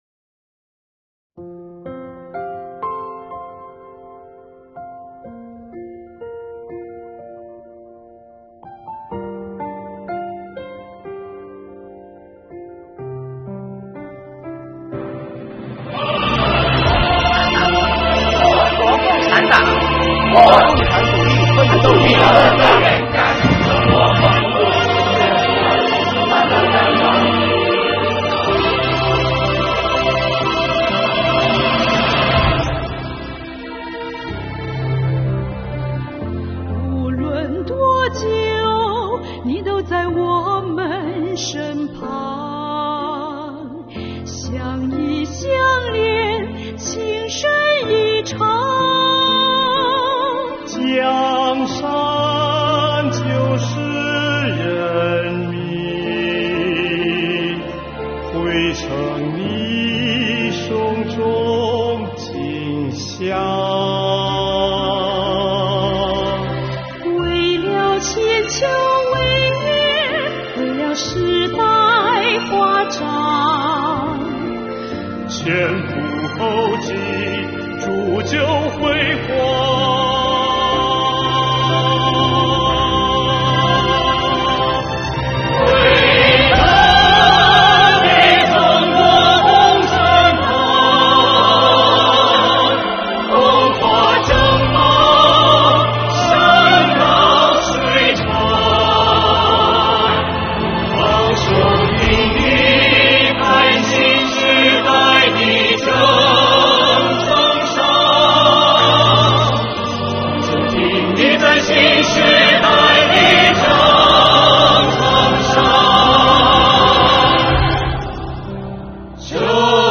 旋律激昂，催人奋进
通过激扬的歌声和生动的画面